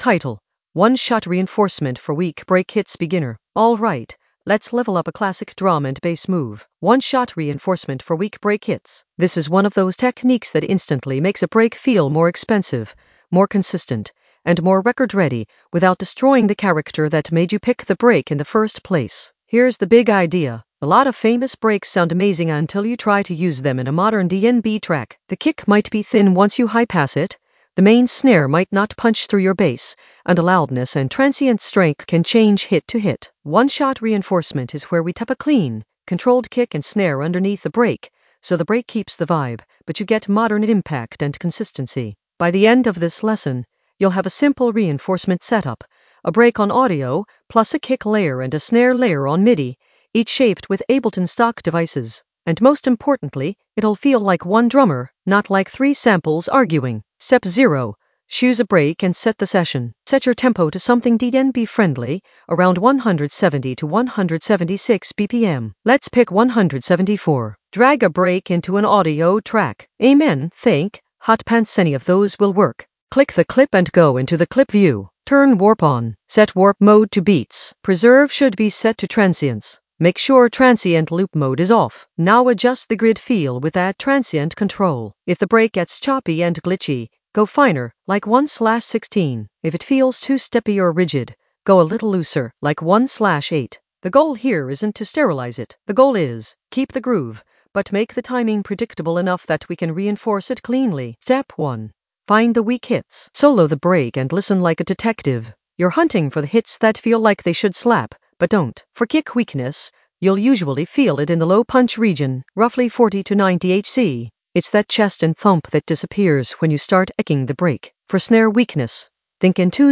Narrated lesson audio
The voice track includes the tutorial plus extra teacher commentary.
one-shot-reinforcement-for-weak-break-hits-beginner-drums.mp3